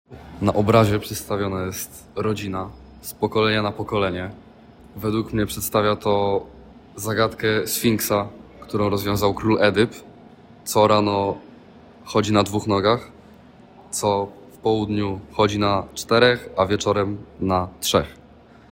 Intervention: Audioguide in Polish